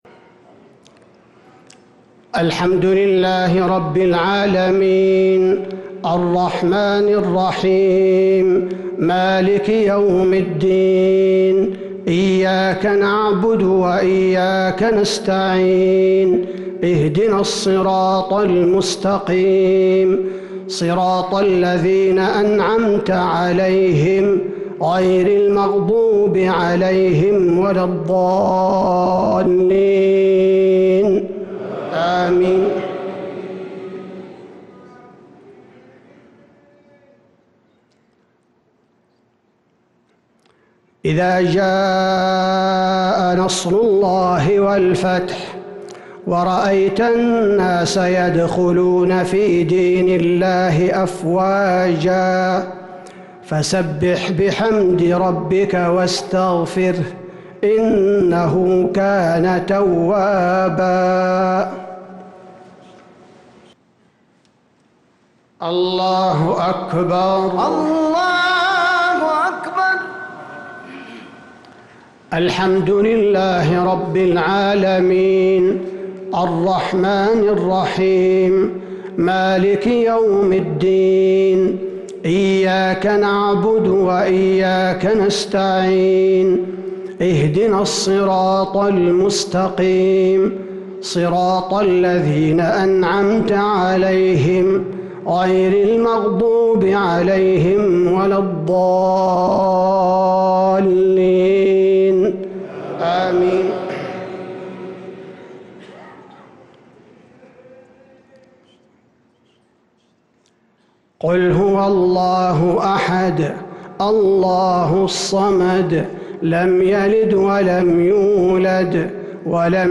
صلاة الجمعة ٤ ذو القعدة ١٤٤٣هـ سورتي النصر و الإخلاص | Jumaah prayer from Surah an-Nasr & al-Ikhlas 3-6-2022 > 1443 🕌 > الفروض - تلاوات الحرمين